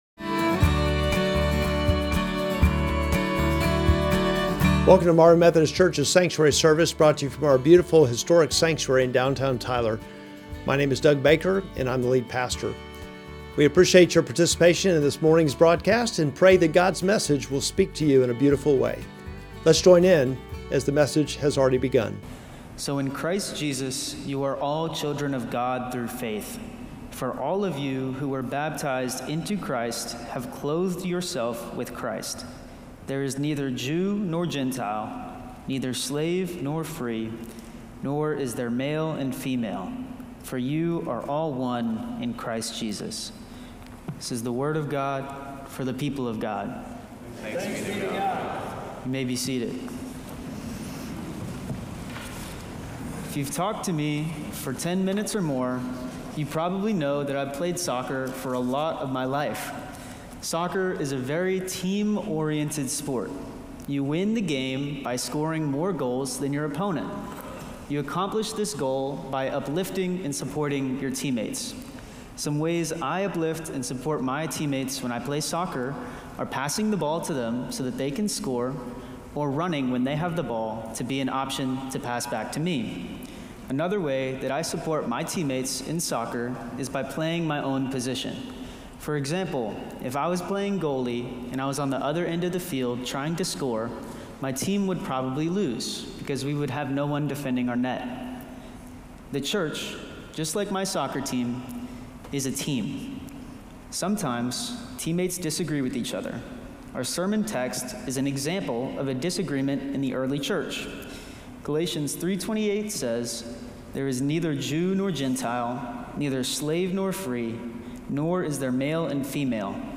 Sermon text: Galatians 3:26-28